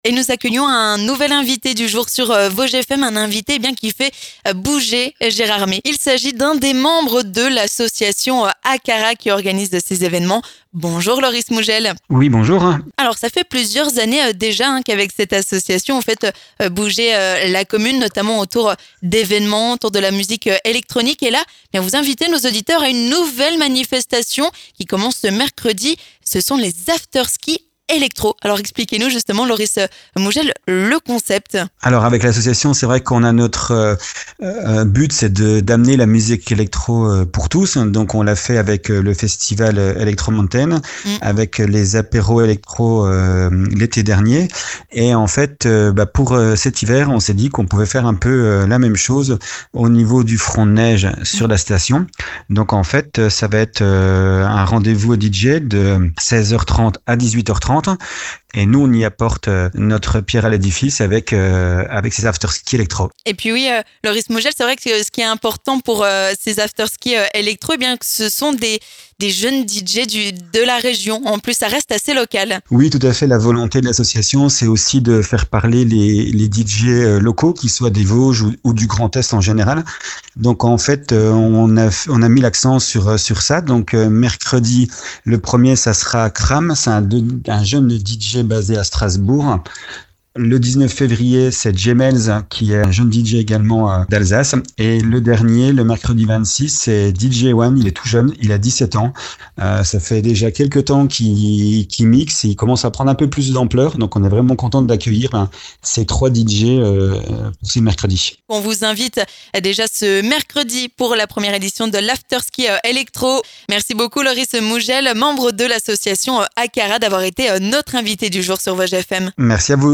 L'invité du jour